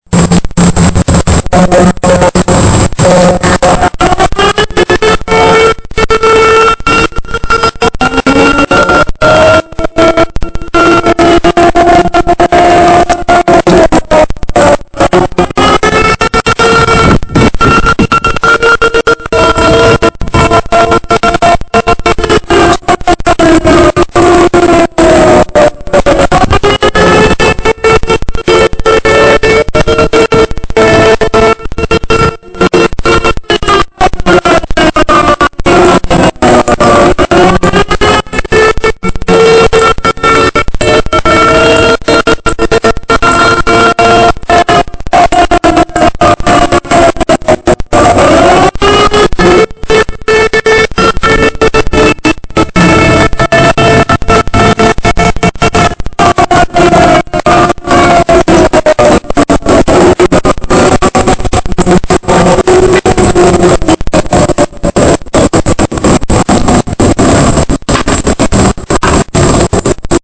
Upon activation of a fire alarm box, the sirens in the village are activated, and the alarm box signal is transmitted to the police dispatch desk, all 3 fire stations, and over the fire horns on Transit Road.
Depew Municipal Fire Alarm System Sound
Depew-Municipal-Fire-Alarm-System-Sound.mp3